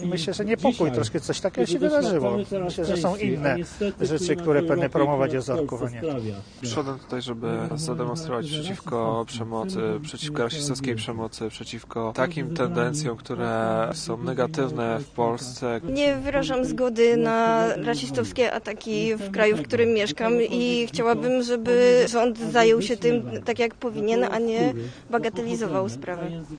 Uczestnicy o proteście